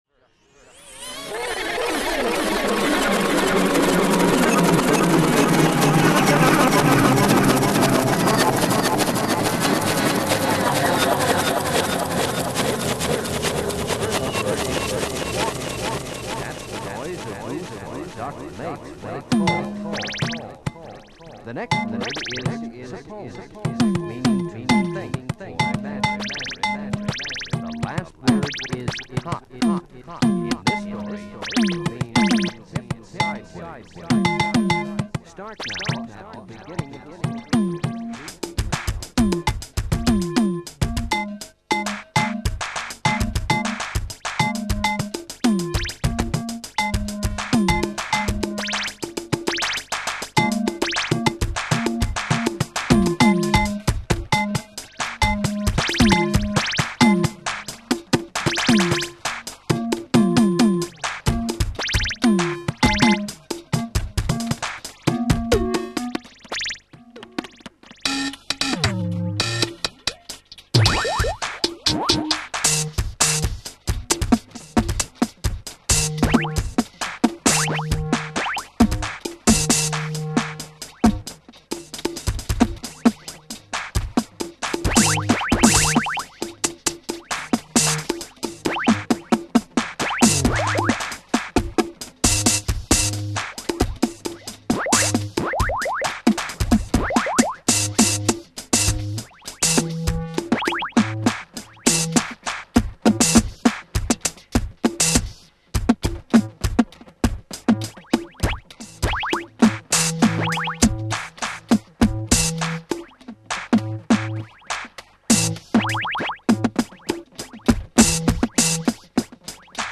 Blip electronica live